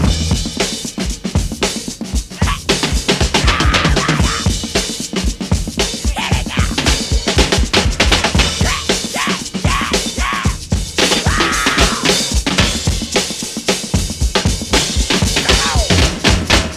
• 115 Bpm Breakbeat E Key.wav
Free breakbeat sample - kick tuned to the E note.
115-bpm-breakbeat-e-key-XMR.wav